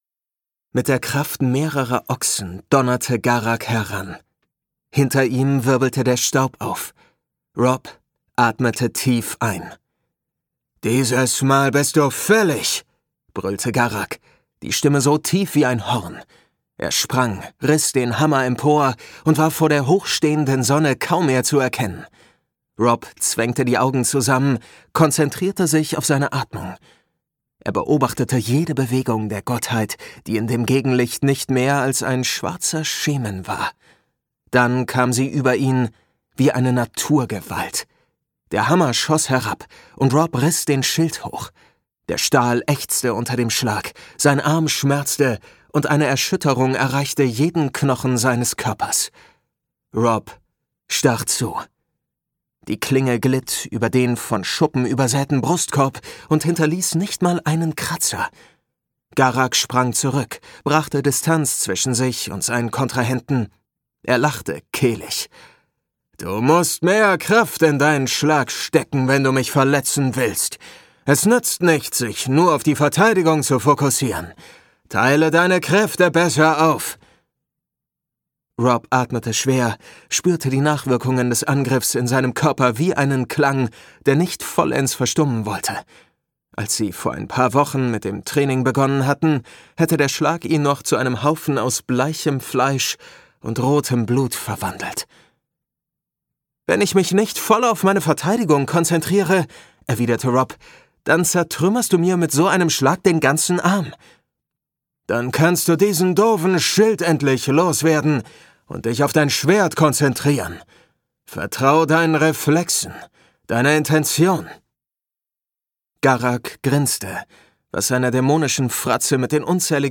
Eternity Online 3 - Mikkel Robrahn | argon hörbuch
Gekürzt Autorisierte, d.h. von Autor:innen und / oder Verlagen freigegebene, bearbeitete Fassung.